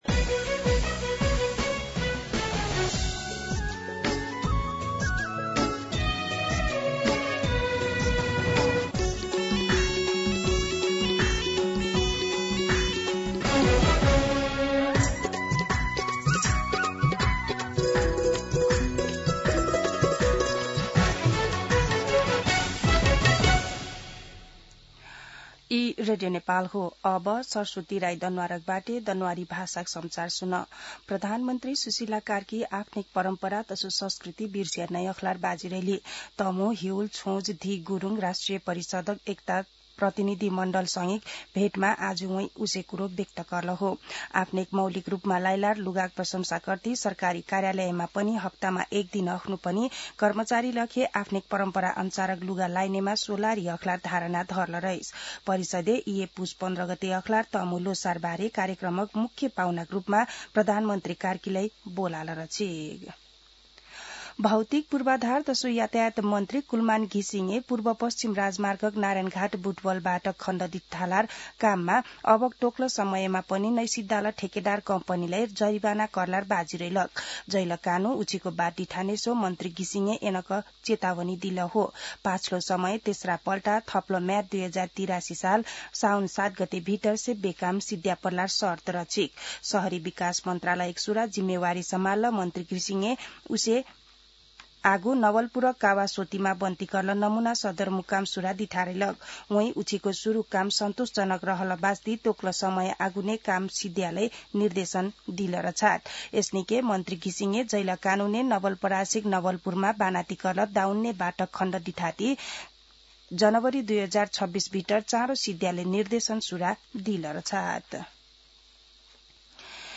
An online outlet of Nepal's national radio broadcaster
दनुवार भाषामा समाचार : ६ पुष , २०८२
Danuwar-News-9-6-.mp3